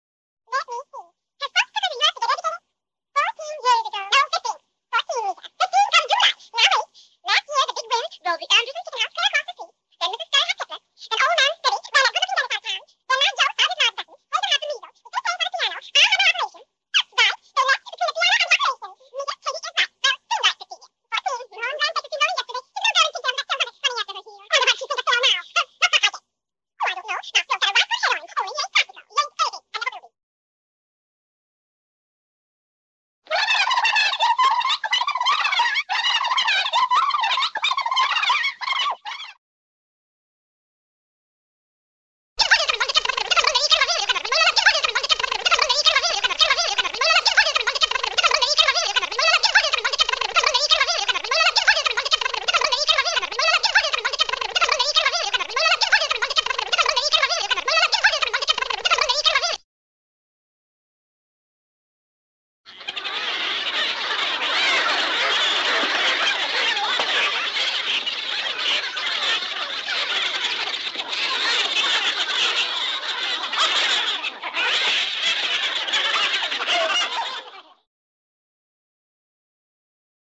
Трёп, ускоренные голоса (сборка)